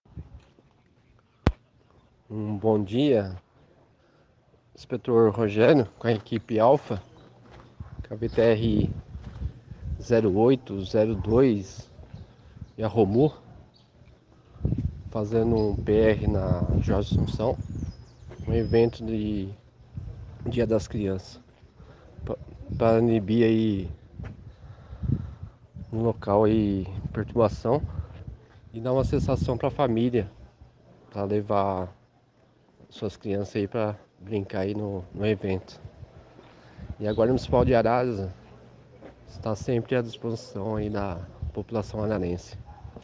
que falou com exclusividade com a nossa reportagem